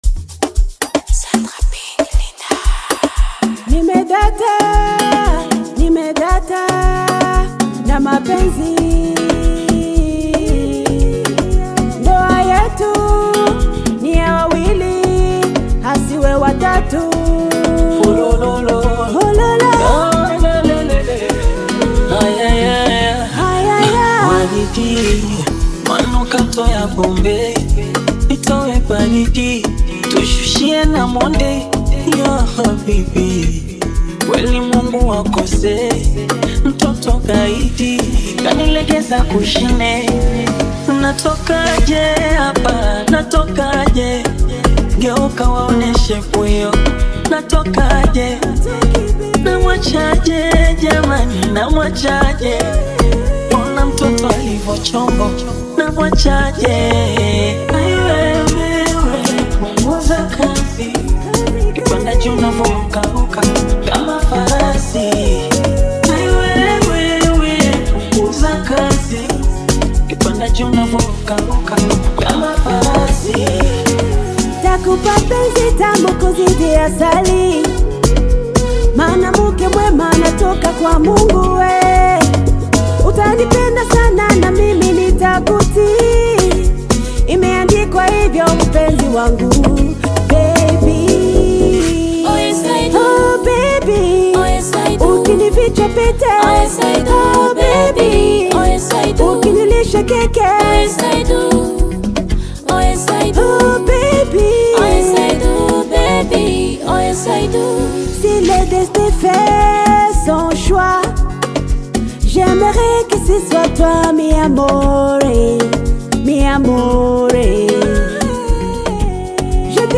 Genre: Amapiano